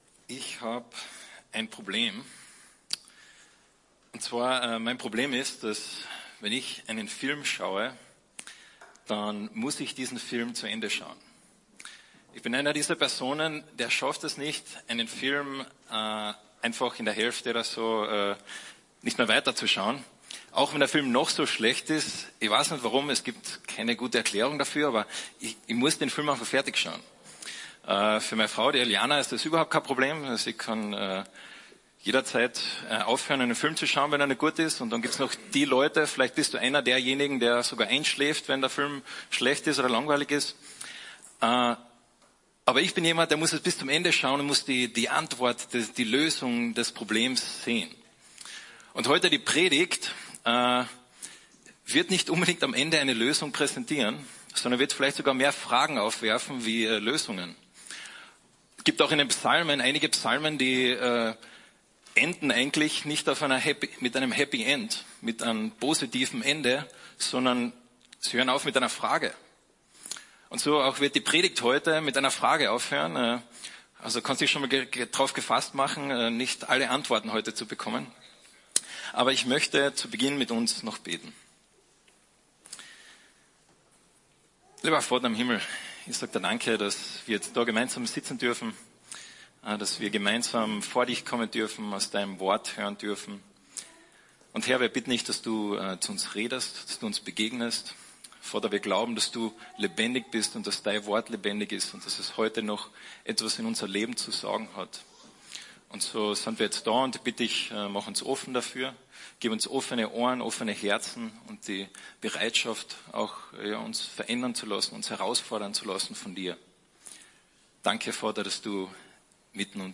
Eine predigt aus der serie "Exodus im Leben." Gott begegnet dem Volk am Berg Sinai.